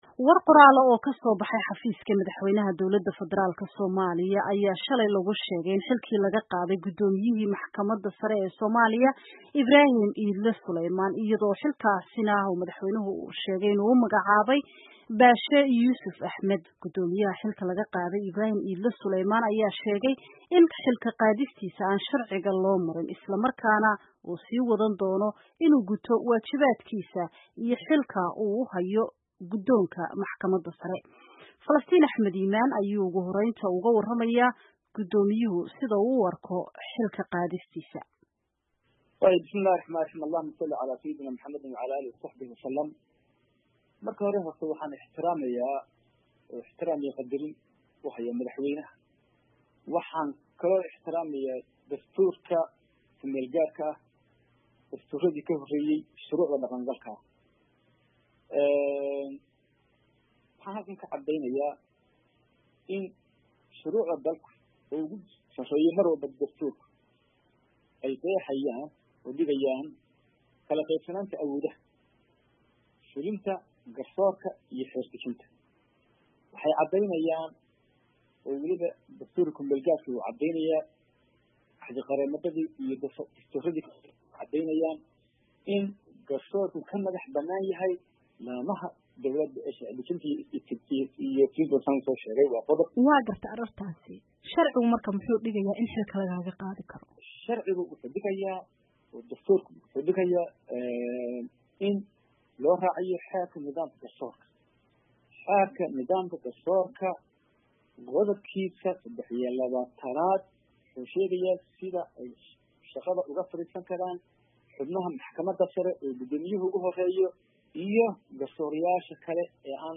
Dhagayso waraysi ay la yeelatay Gudoomiyaha VOA